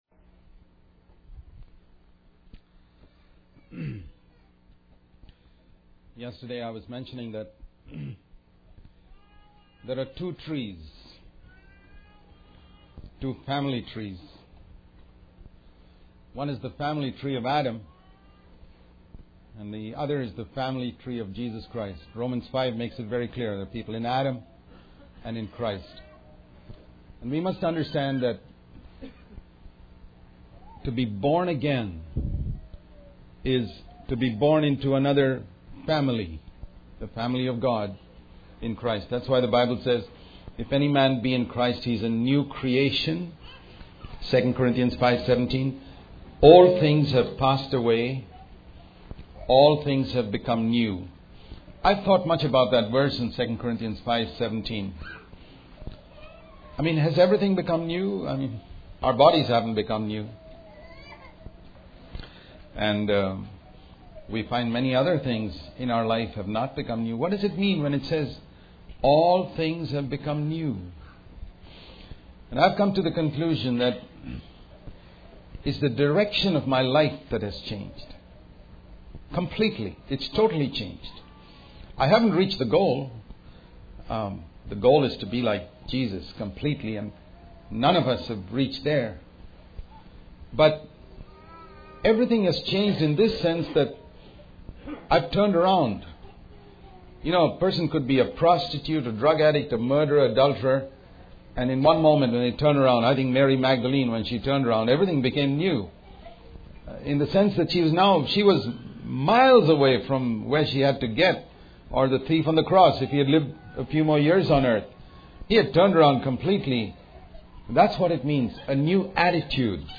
In this sermon, the speaker emphasizes the importance of listening in prayer and in life. He contrasts the idea of constantly speaking and doing with the value of taking time to listen.